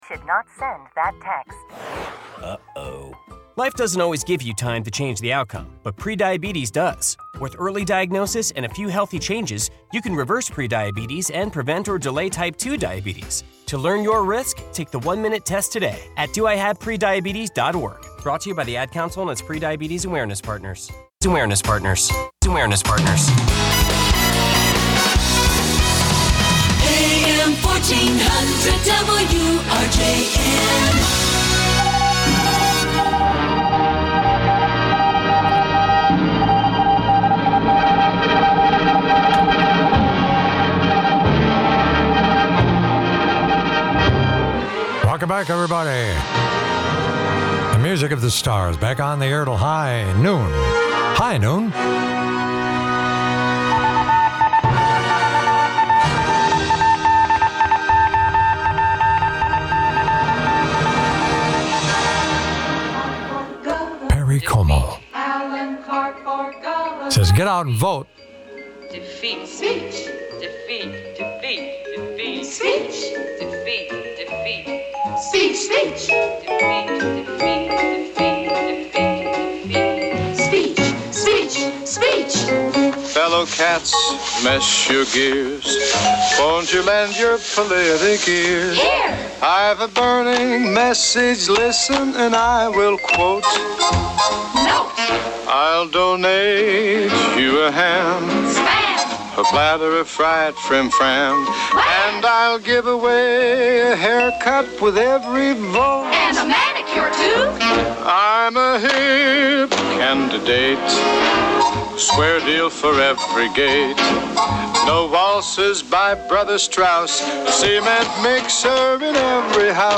Broadcasts live 7 a.m. to noon Sunday mornings across Wisconsin.